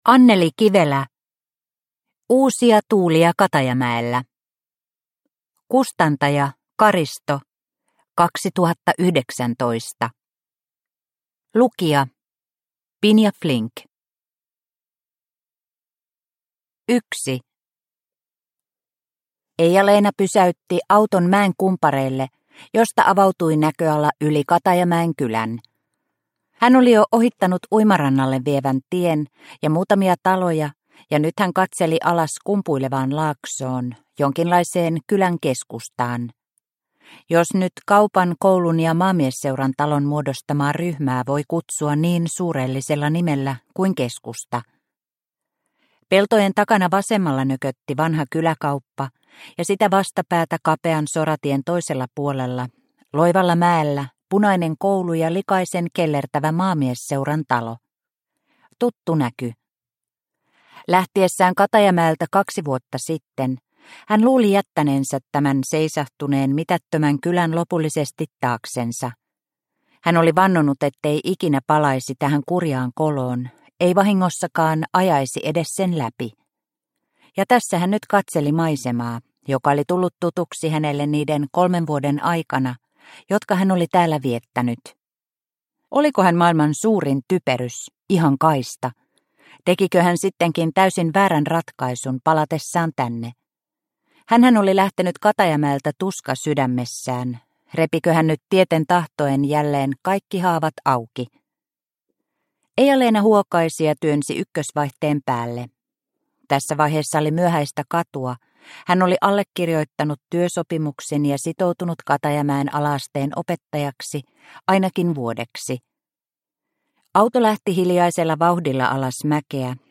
Uusia tuulia Katajamäellä – Ljudbok – Laddas ner